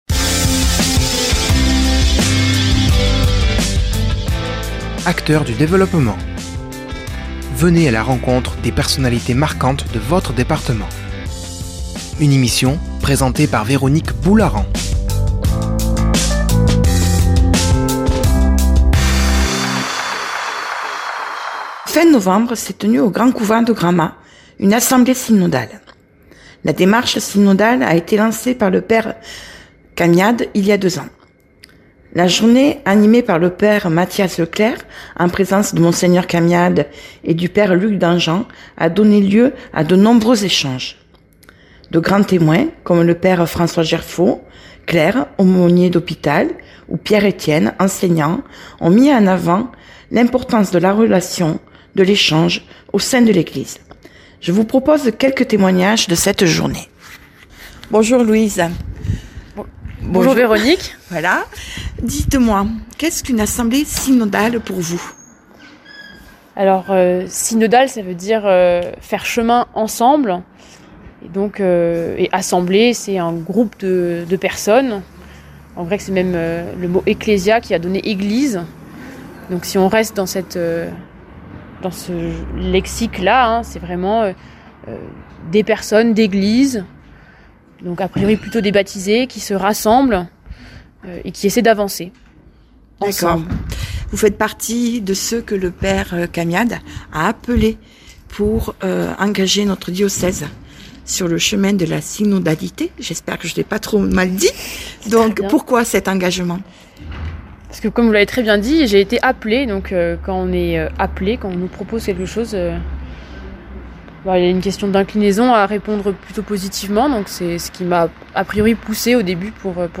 s’est déplacé à Gramat dans le cadre d’une assemblée synodale